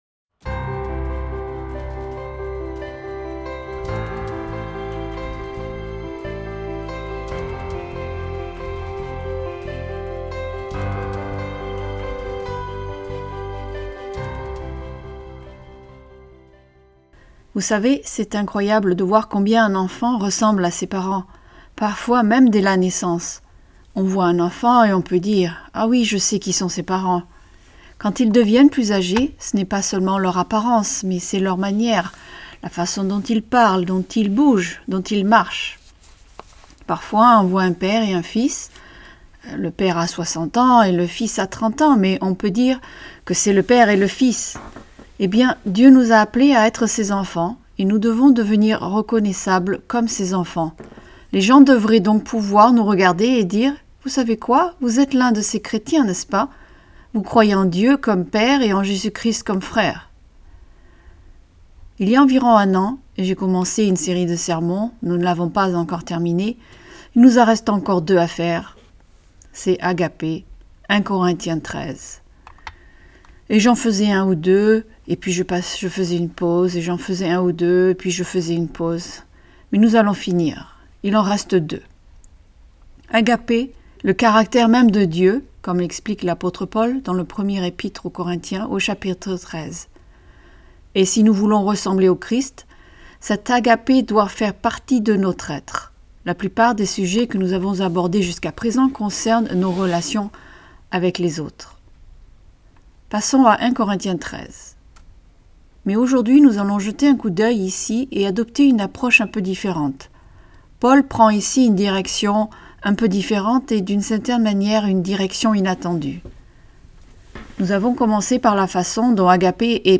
Ce sermon discute et explique le fait que l’amour ne se réjouit pas de l’injustice.